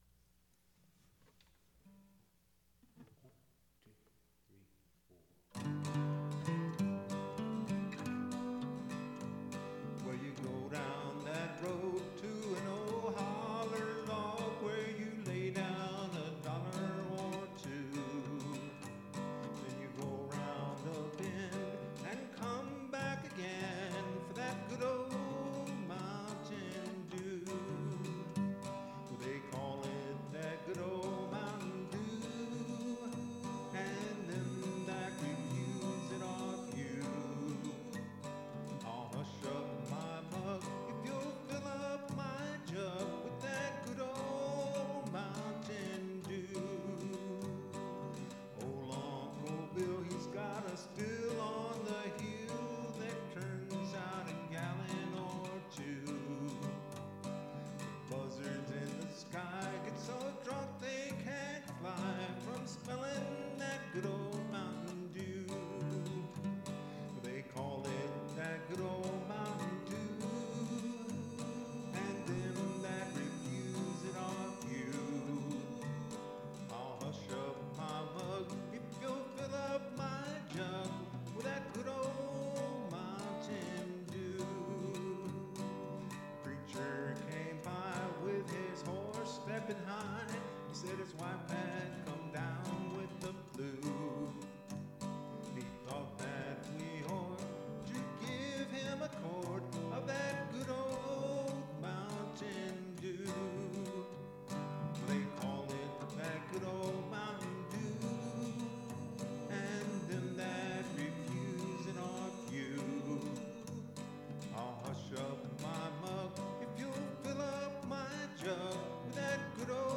MP3 Copy of Studio Recording
Guitar
Vocal